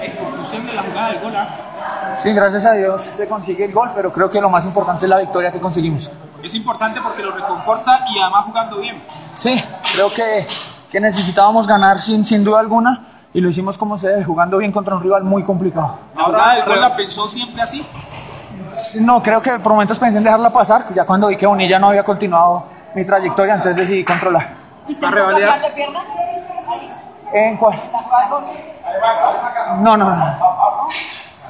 Luego de la victoria, Mackalister explicó, en una declaración corta, que más allá del gol, lo más importante es la victoria del equipo y la forma en la que se le ganó a un equipo duro como es este Equidad de Luis Fernando Suárez.